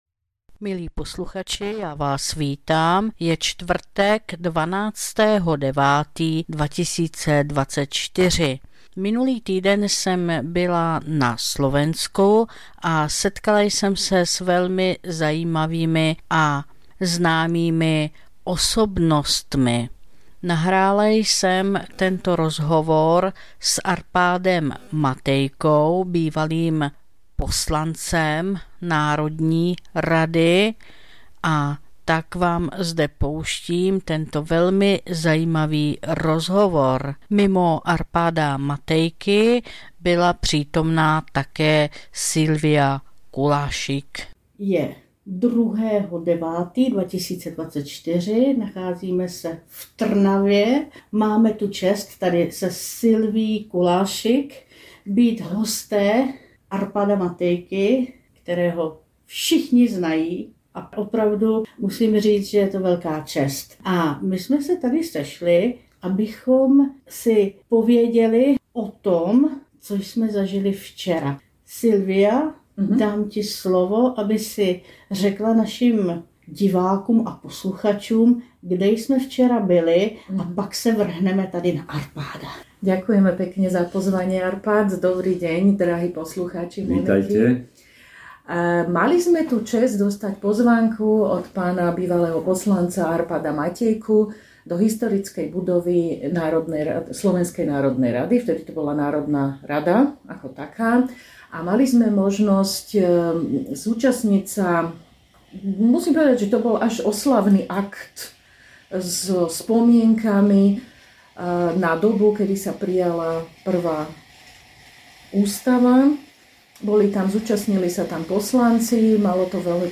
2024-09-12 – Studio Itálie – Slovensko: Rozhovor: Arpád Matejka ex politik VPN a HZDS a ex poslanec SNR a NR SR.